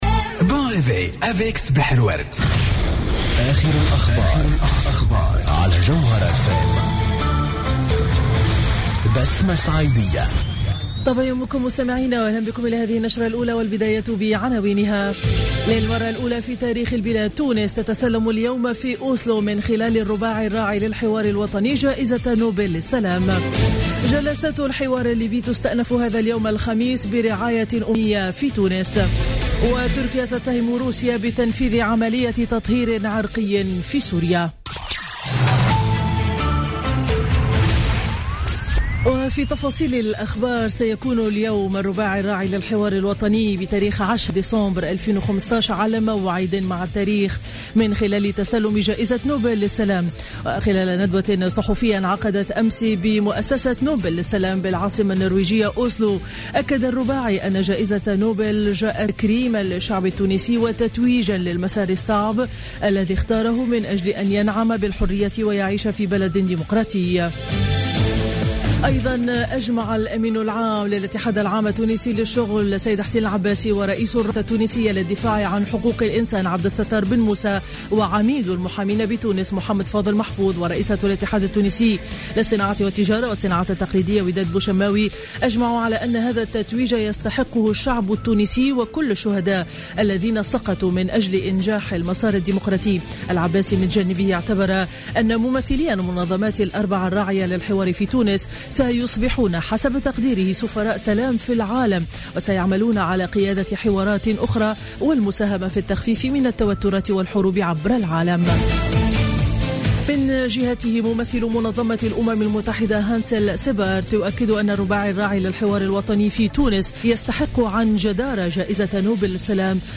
نشرة أخبار السابعة صباحا ليوم الخميس 10 ديسمبر 2013